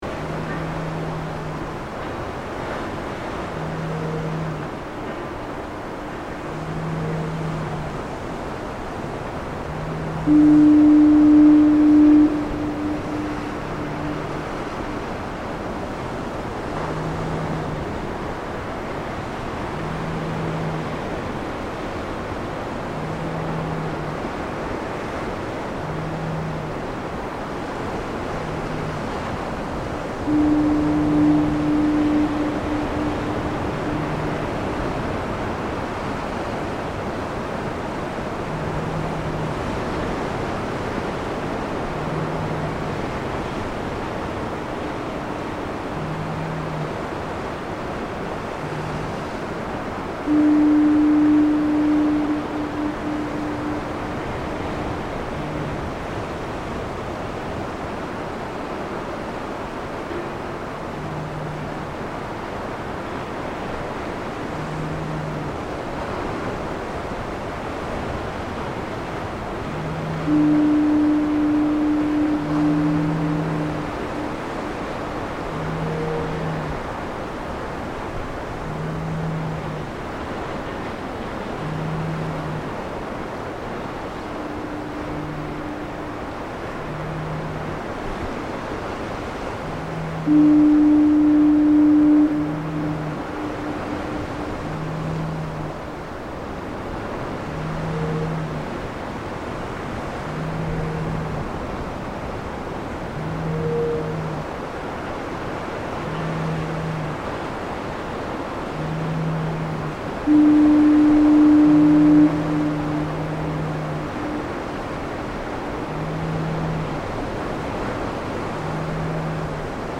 Amphitrite Point Lighthouse in Ucluelet BC on a foggy evening. You can hear the fog horn every 20 seconds. You can also hear a whistle buoy for starboard aid and a bell buoy for port aid. And of course the pacific ocean.